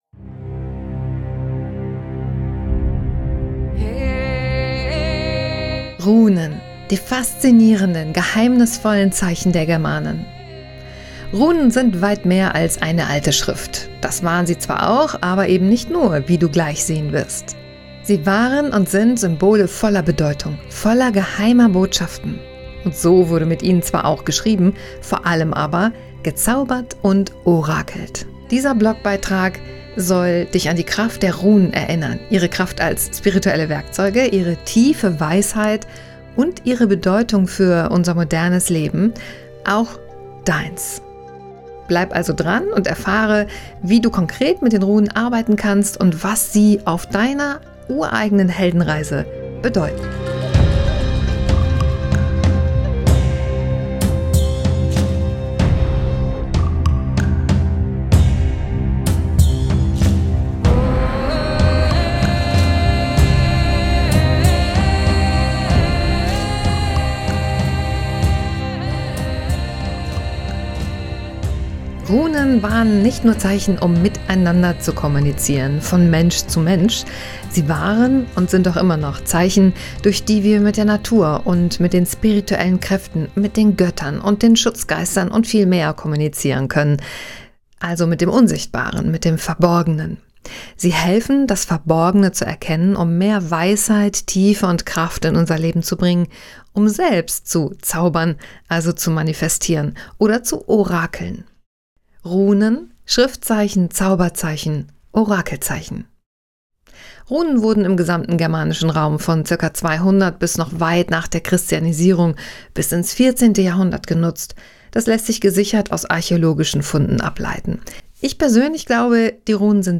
Du kannst diesen Blogbeitrag unten weiterlesen oder dir von mir erzählen lassen, denn ich habe ihn für dich eingesprochen, dafür einfach hier unten auf den Pfeil klicken: